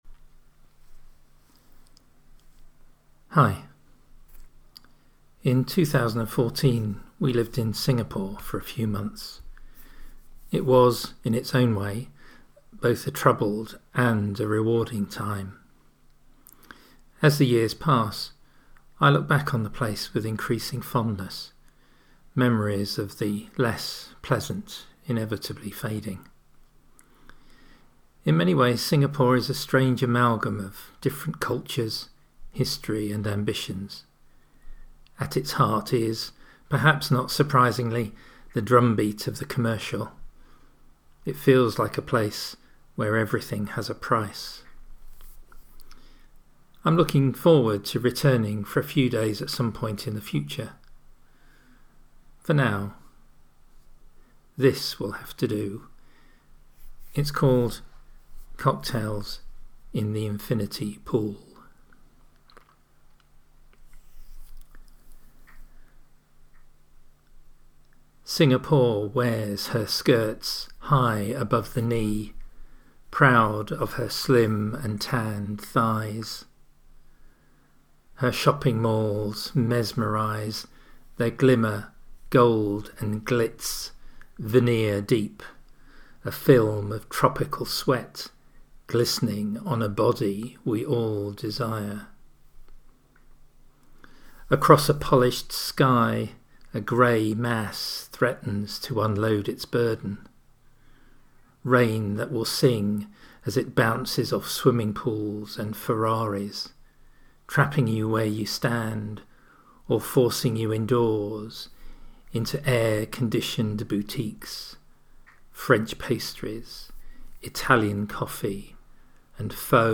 ‘Cocktails in the Infinity Pool’ – a reading